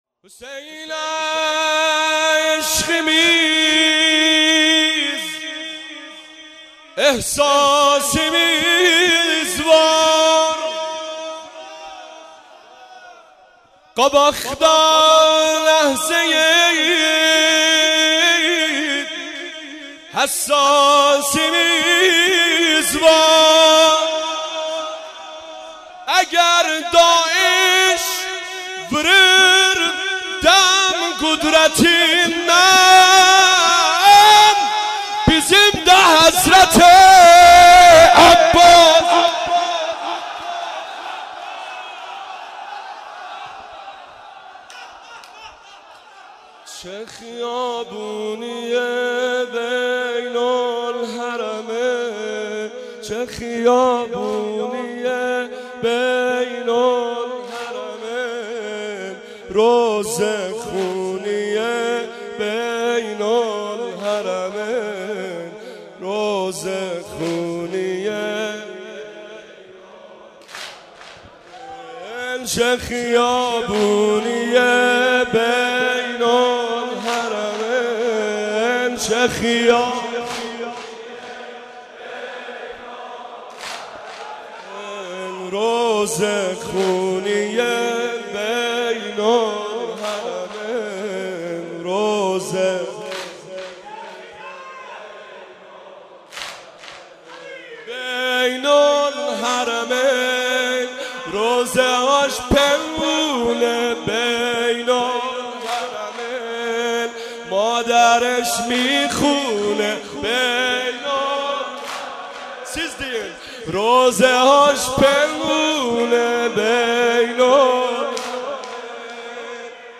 سینه زنی سنگین
مجمع حیدریون زنجان